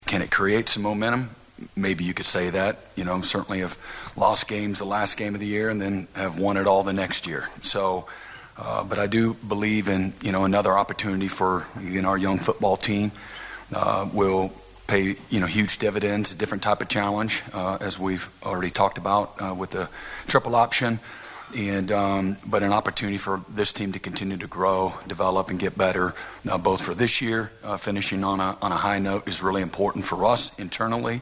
Sooner head coach Brent Venables talks about what a win over the Midshipmen would mean for OU football.